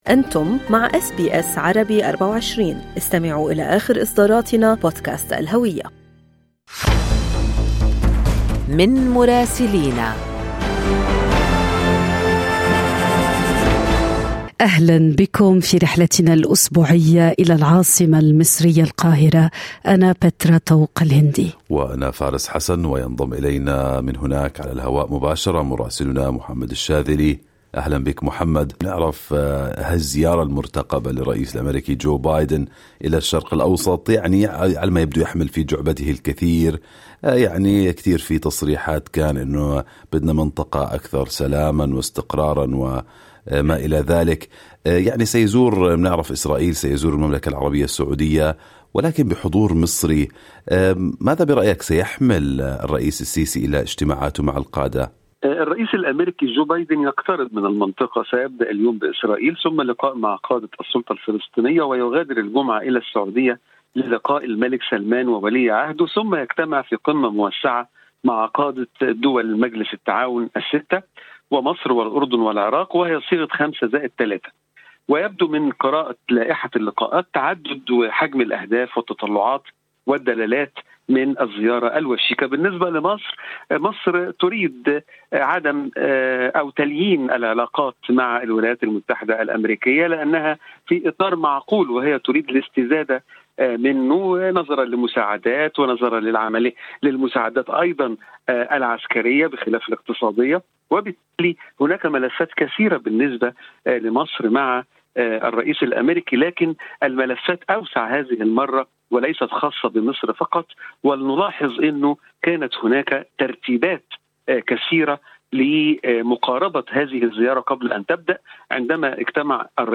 من مراسلينا: أخبار مصر في أسبوع 13/7/2022